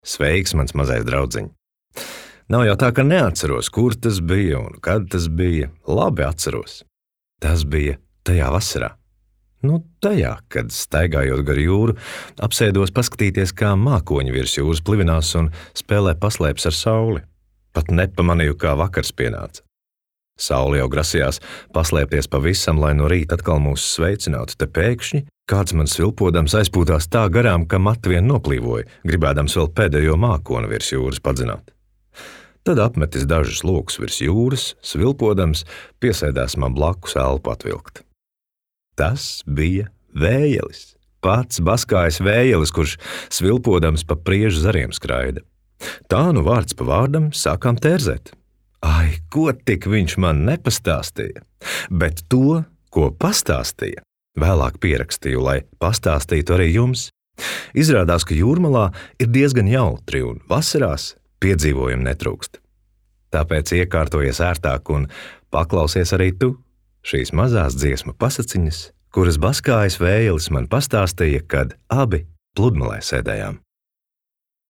Dziesmas un dziesmu pavadījumi.
taustiņi
ģitāra
perkusijas.